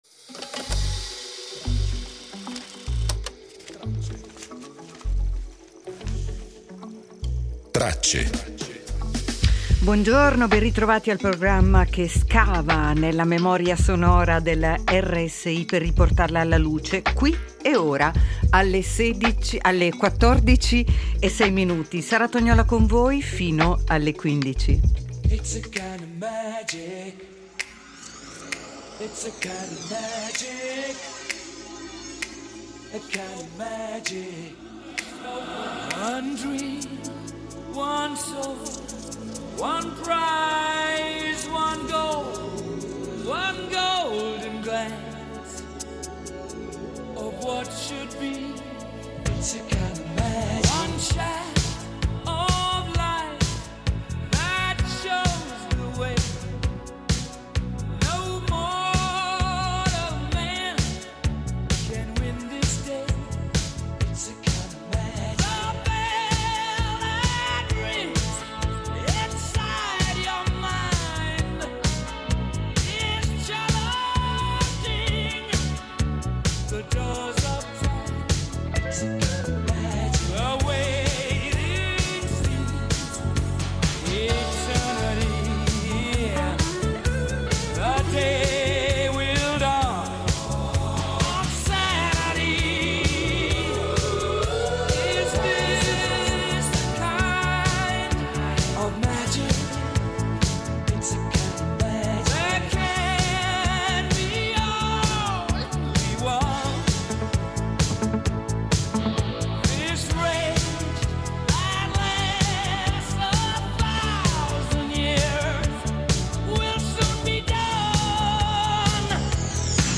Cinema Publishers Collection Contenuto audio Disponibile su Scarica Anche oggi vi proponiamo un viaggio tra frammenti sonori che continuano a raccontare il nostro tempo: la voce di Eugenio Scalfari , fondatore di la Repubblica ; la scrittura essenziale di Hemingway ne Il vecchio e il mare ; il mito di Humphrey Bogart ; e la luce cosmopolita di Caterina Valente .